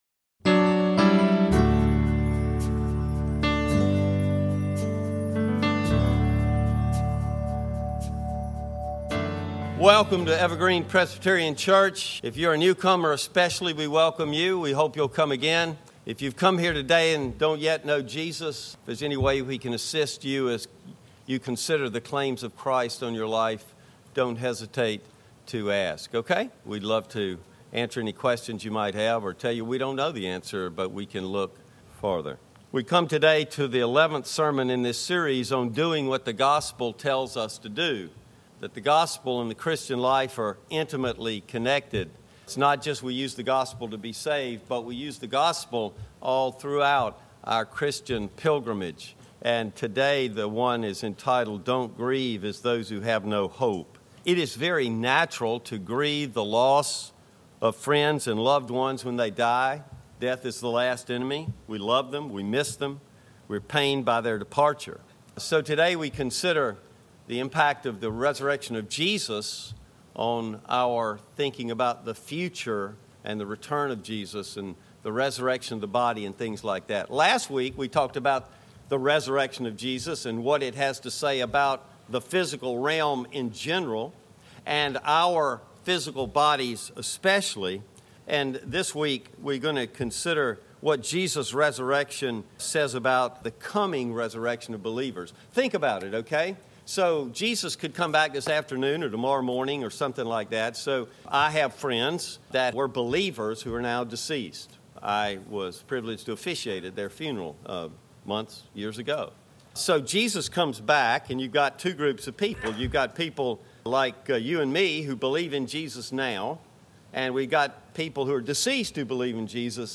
No. 11 - Don't Grieve as Those Who Have No Hope - Evergreen Presbyterian Church